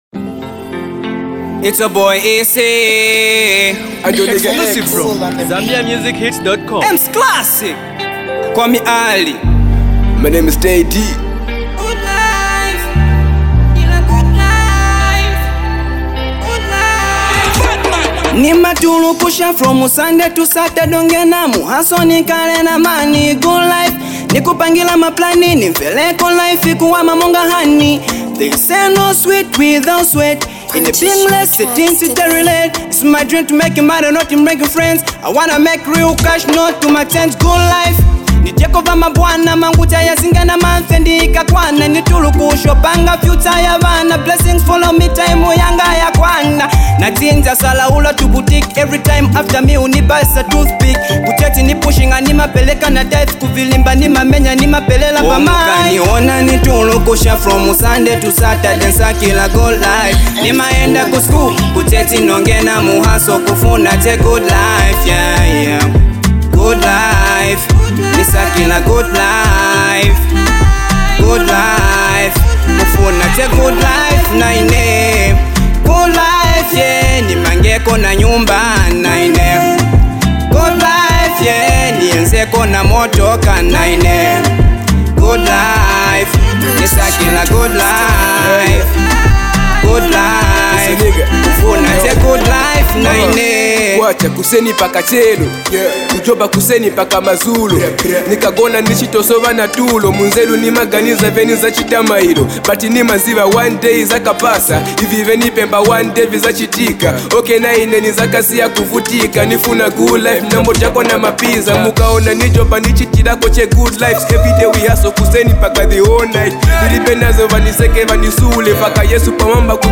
Talented uprising Rapper